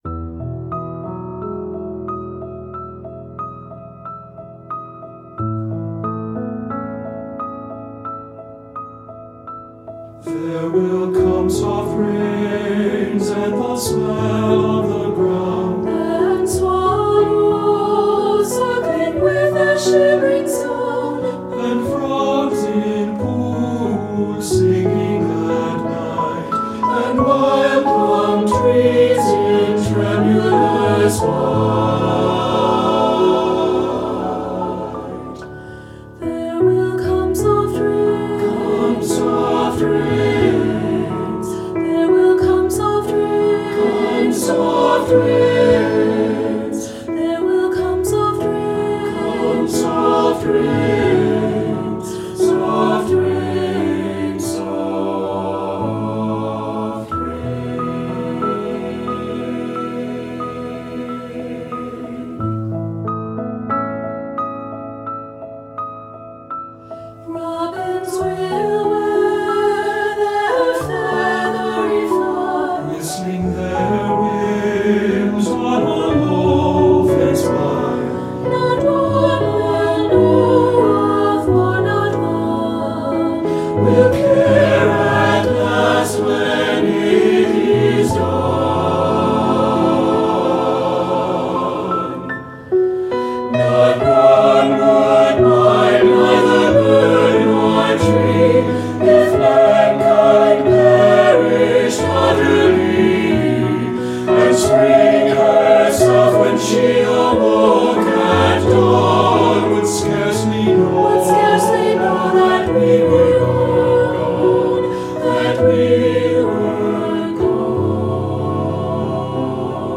Choral Concert/General
Now available for SATB voices!
Haunting and harmonic, the music captures the ear.
SATB